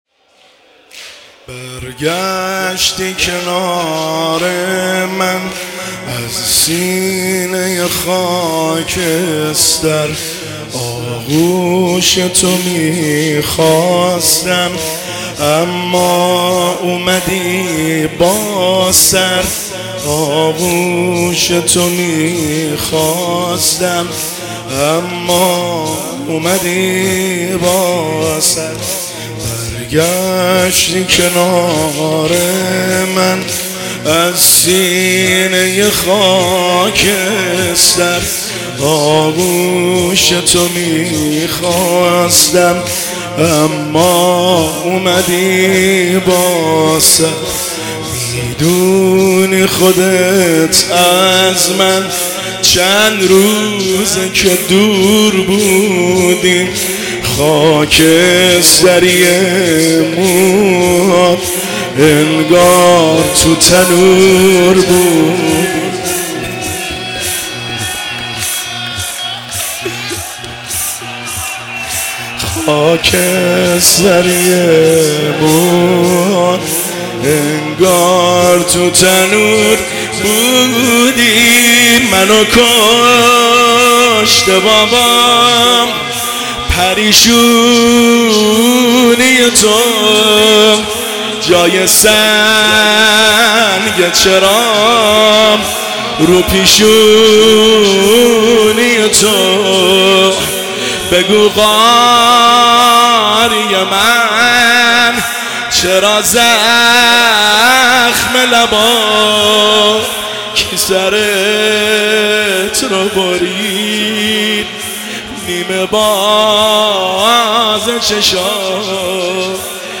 زمینه – شب سوم محرم الحرام 1404